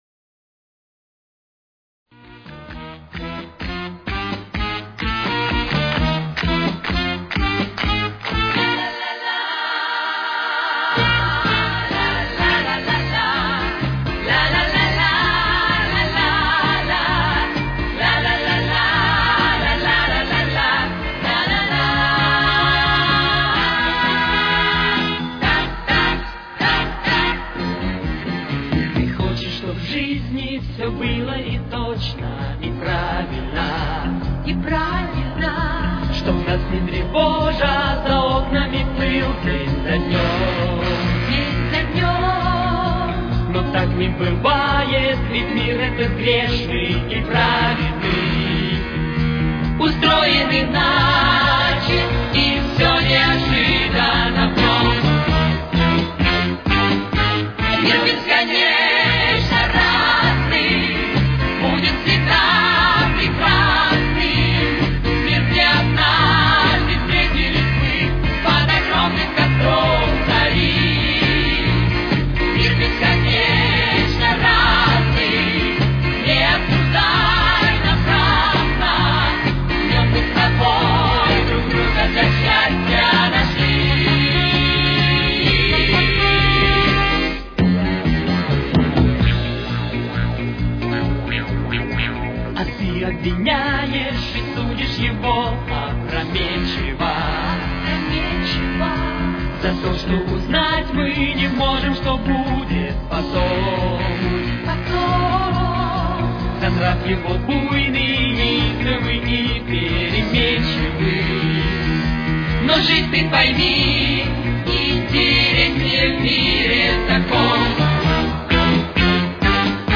с очень низким качеством (16 – 32 кБит/с)